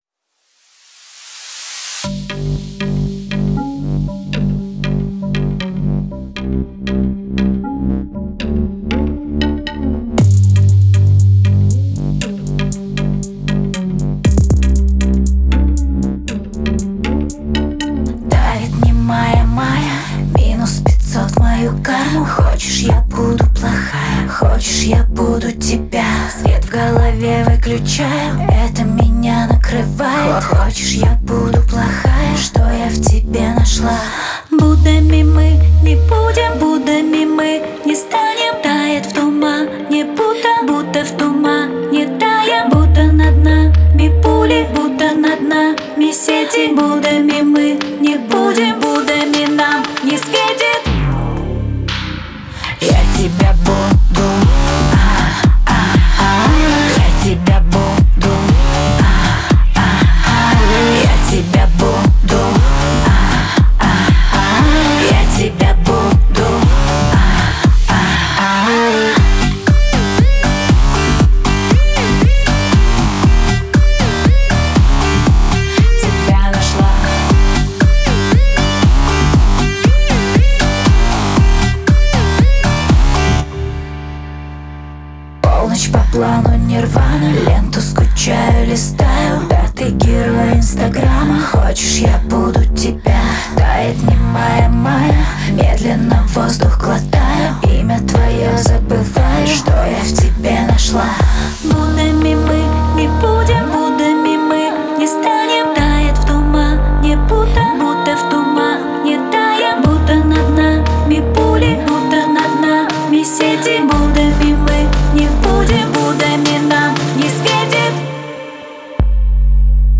запоминающимся мелодичным хором и выразительным вокалом